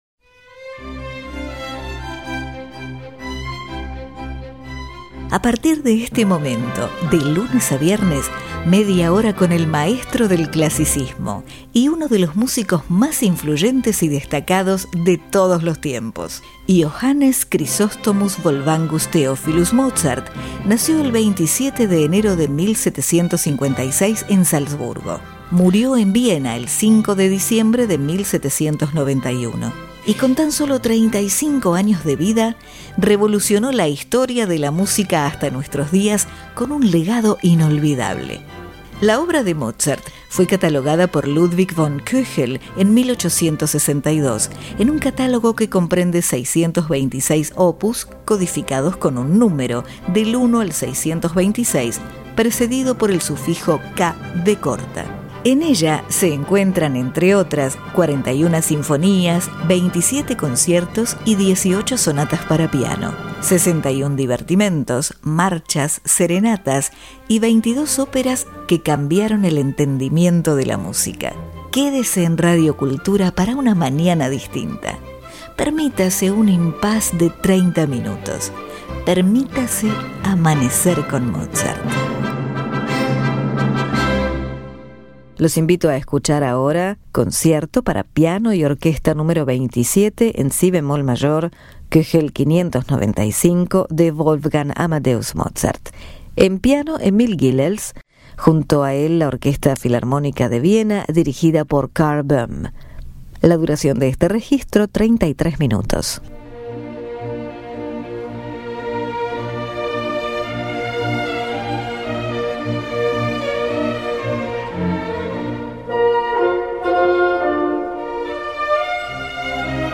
Concierto Para Piano Y Orquesta Nº 27 En Si Bemol Mayor K. 595 Solista: Emil Gilels (Piano) Orquesta Filarmónica De Viena Director: Karl Böhm